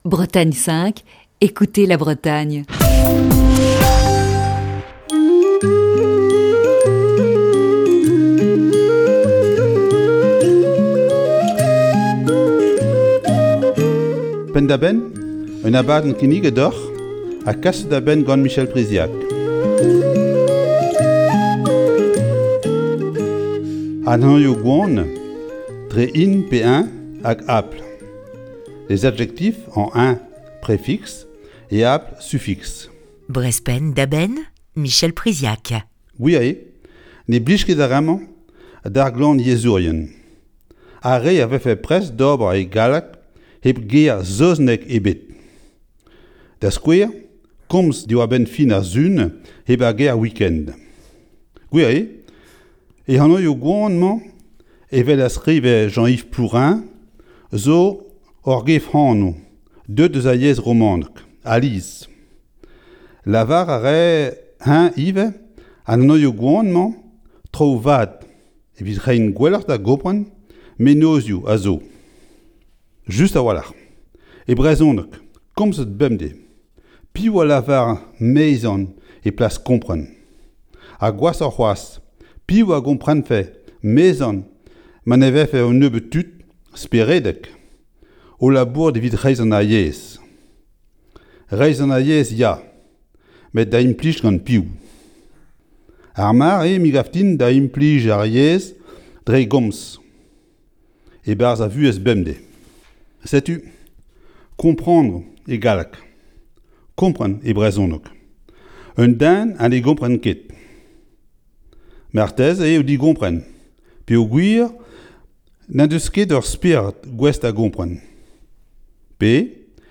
Chronique du 17 décembre 2020. C'est le cours de breton du jeudi dans Breizh Penn da Benn !